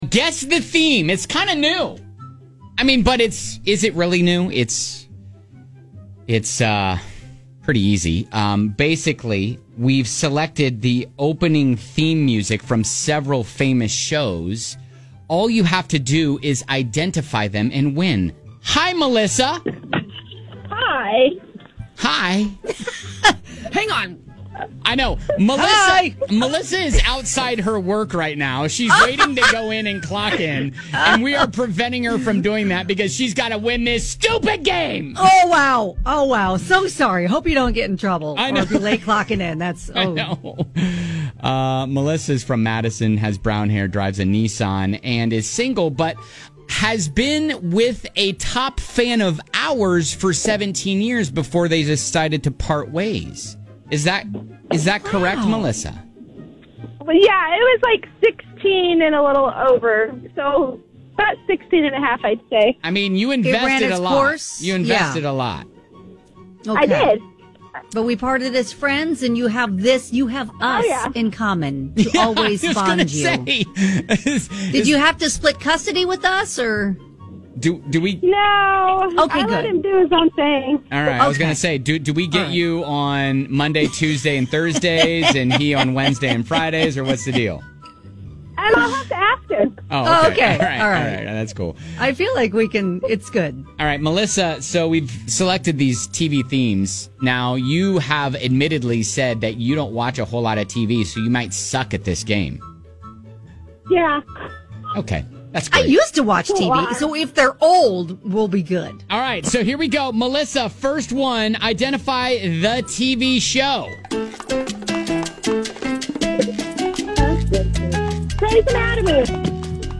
We have selected the opening theme music from several famous shows - identify them and win tickets to Jesse McCartney!